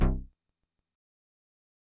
Synth Bass (R.I.P. Screw).wav